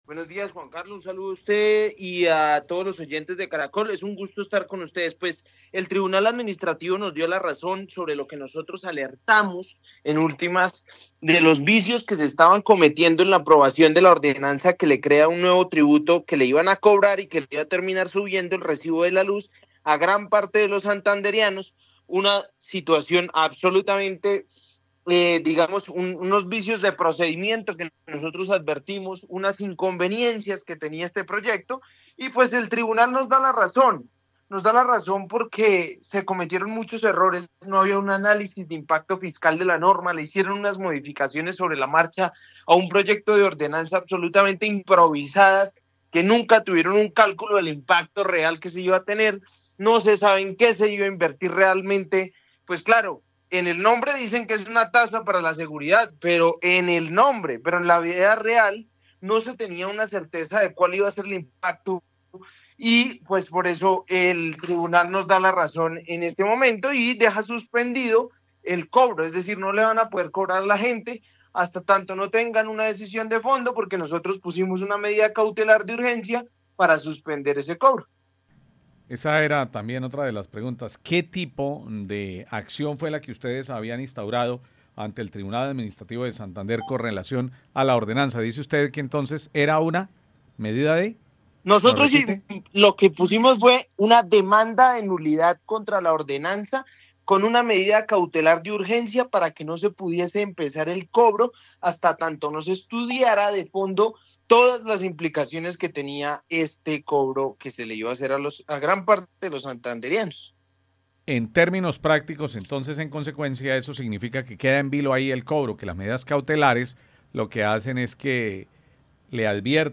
Christian Avendaño, representante a la cámara explica medida judicial contra sobretasa de seguridad de Santander